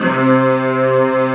14 STRG C2-R.wav